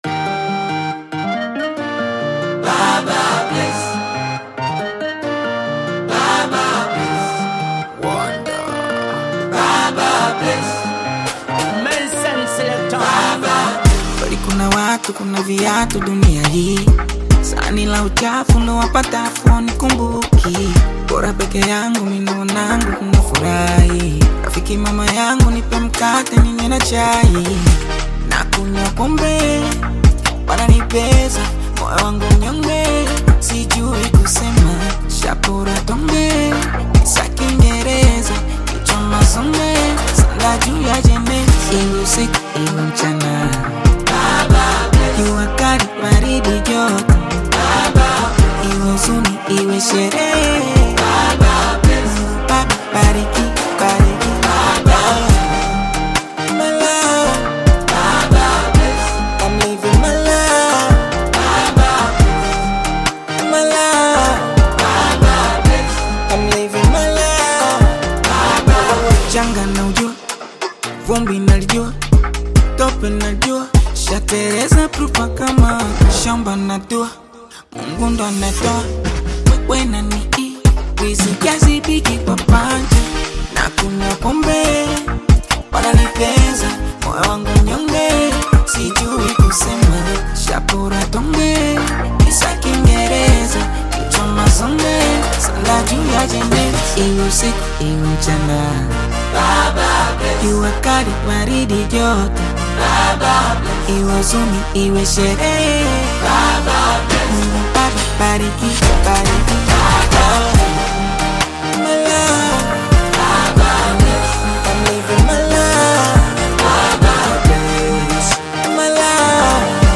upbeat Bongo Flava single